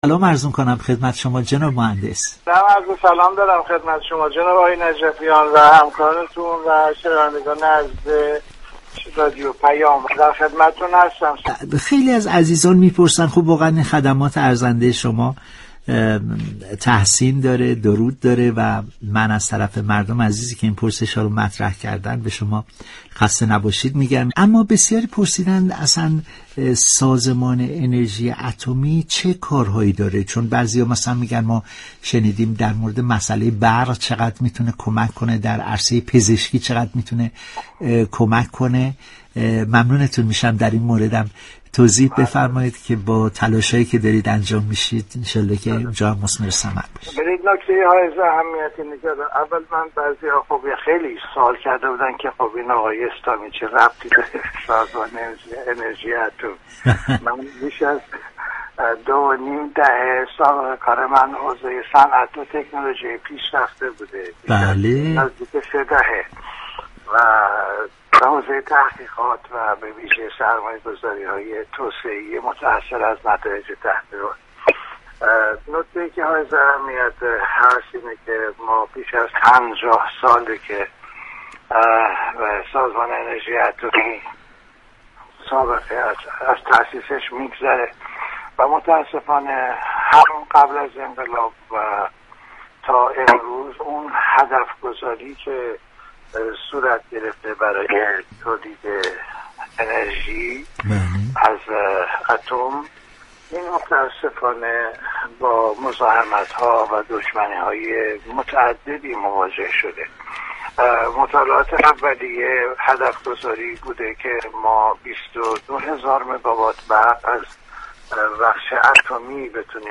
مهندس اسلامی رئیس سازمان انرژی اتمی در گفتگو با رادیو پیام گفت:
مهندس اسلامی رئیس سازمان انرژی اتمی كلیاتی از استفاده انرژی اتمی را در رابطه با مردم، در گفتگو با رادیو پیام بیان كرد.